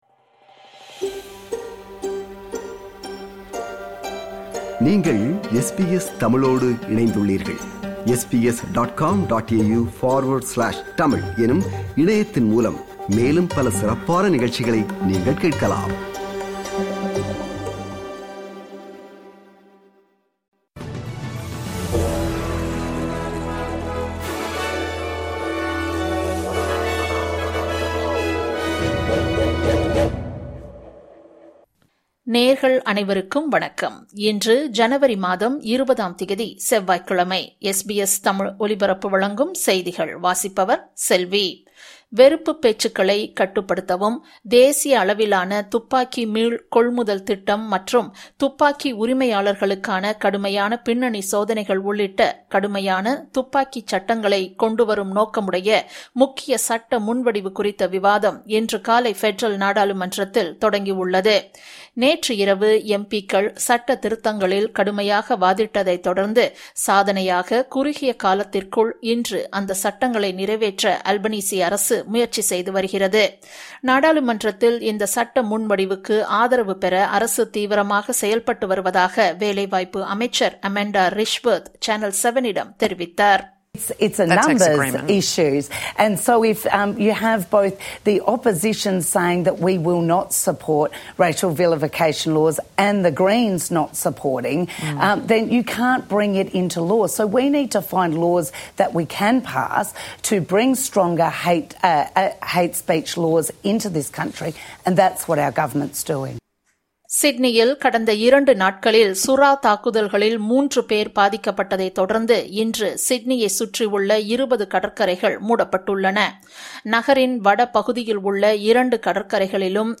SBS தமிழ் ஒலிபரப்பின் இன்றைய (செவ்வாய்க்கிழமை 20/01/2026) செய்திகள்.